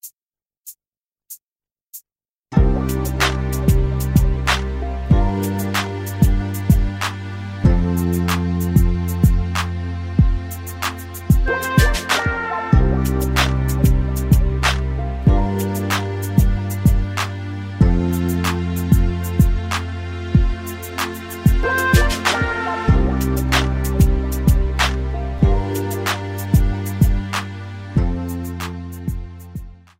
MPEG 1 Layer 3 (Stereo)
Backing track Karaoke
Pop, 2000s